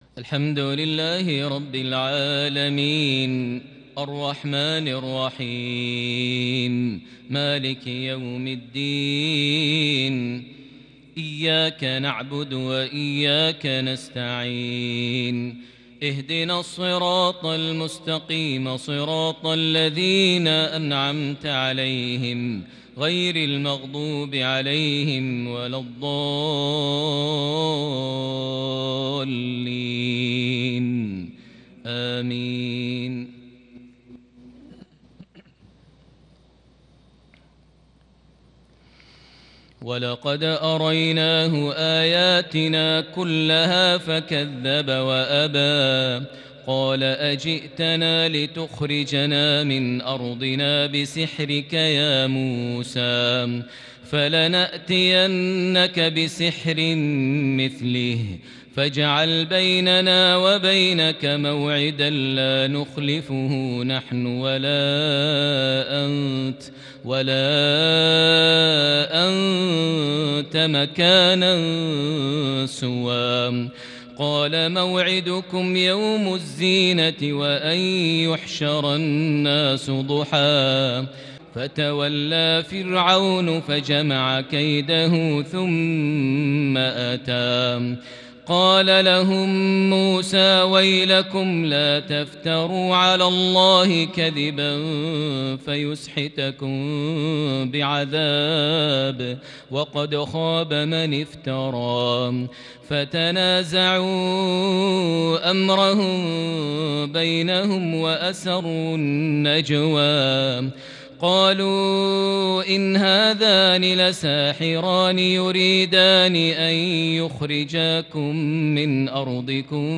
تلاوة كردية متفردة | لسورة طه (56-76) |عشاء الثلاثاء 23 ذو القعدة 1441هـ > 1441 هـ > الفروض - تلاوات ماهر المعيقلي